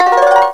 Index of /m8-backup/M8/Samples/Fairlight CMI/IIX/PLUCKED